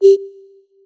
notification_sounds
flute.mp3